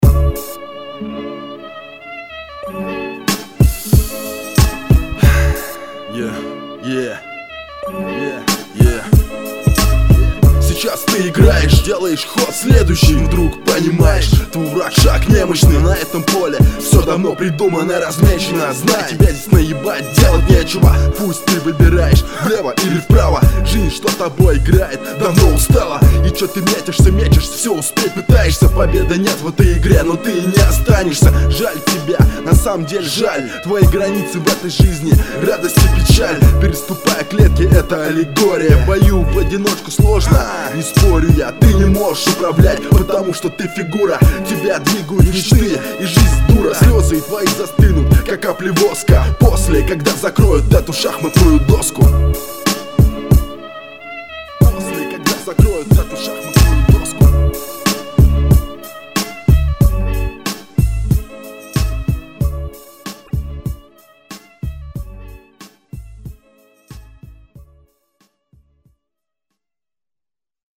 • Жанр: Рэп
... трэк на батл...